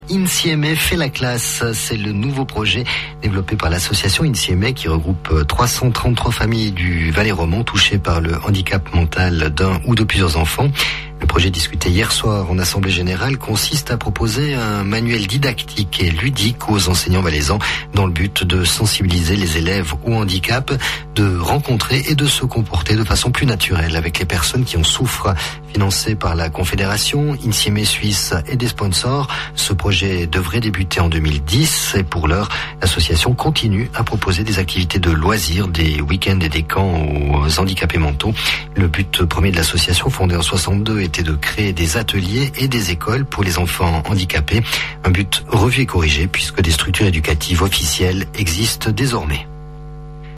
JOURNAL 11 H DU 26 MARS 2009